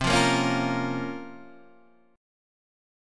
Bbm/C chord